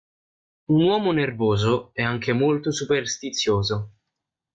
ner‧vó‧so
/nerˈvo.zo/